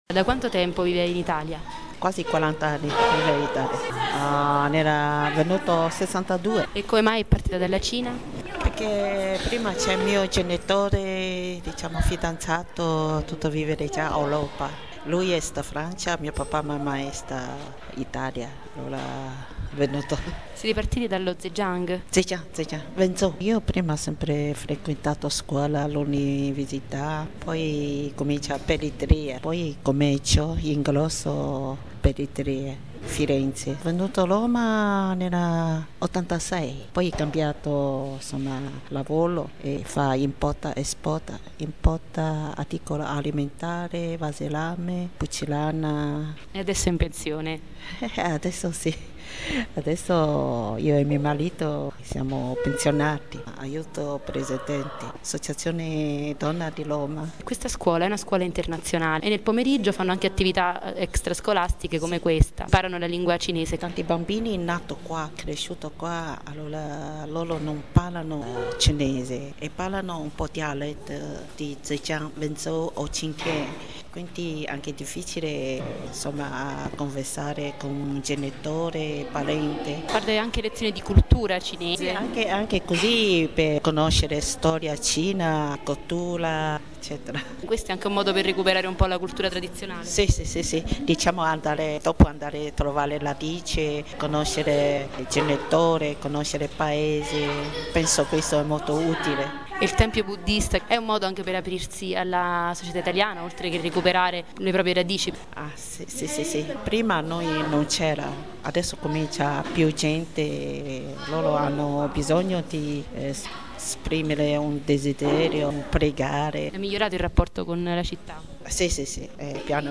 Interviste audio